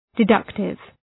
{dı’dʌktıv}